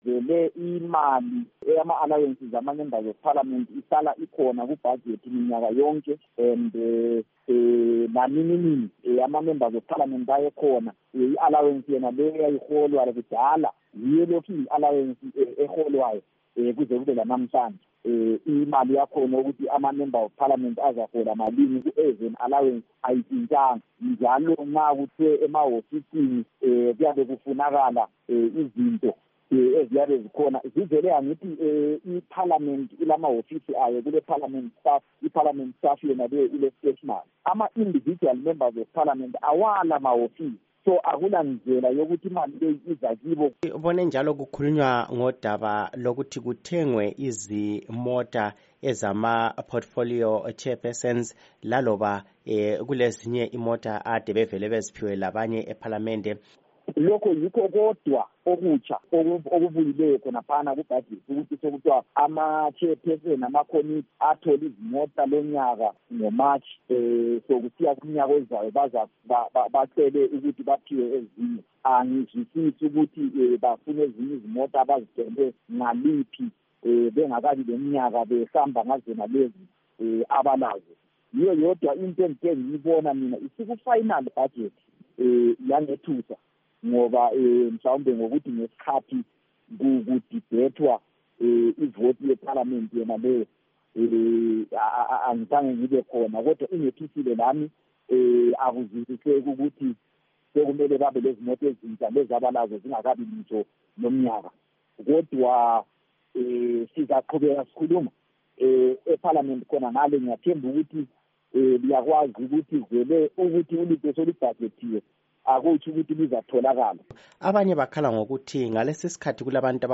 Ingxoxo loMnu Discent Bajila.